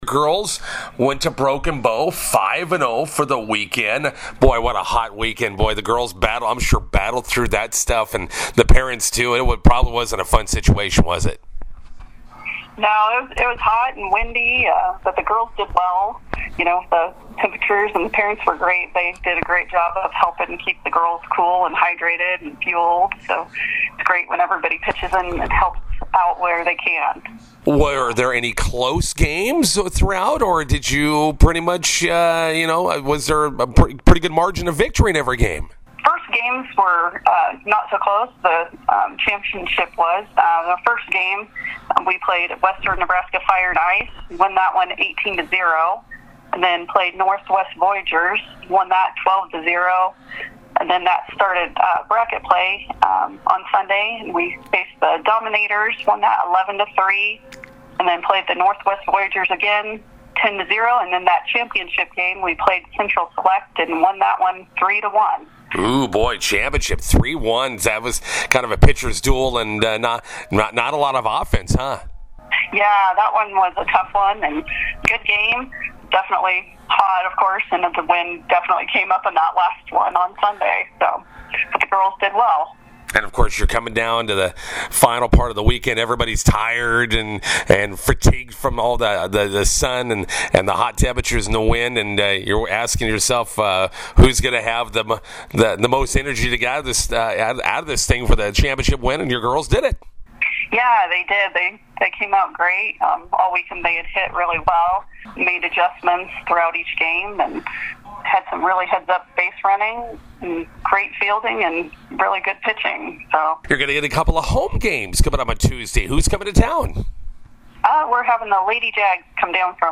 INTERVIEW: McCook Rebels 12-and-under softball wins Broken Bow Tournament, preparing for Class C state this weekend.